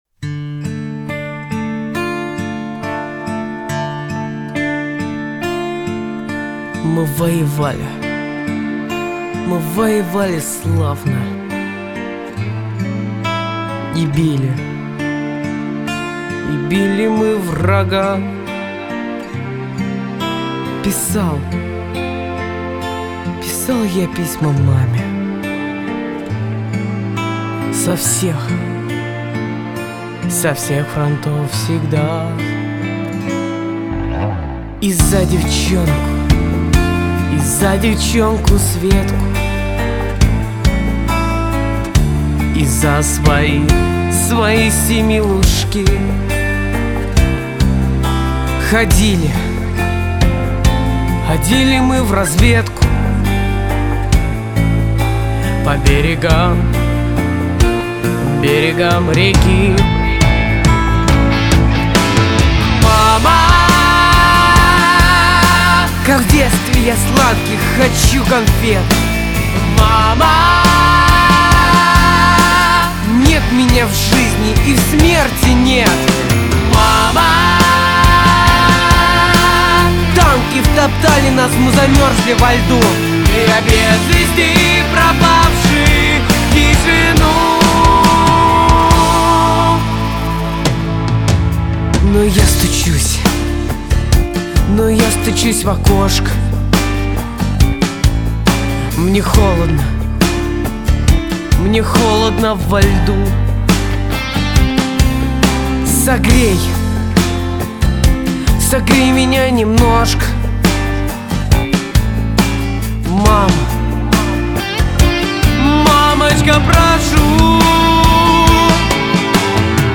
"МАМА" Pop-Rock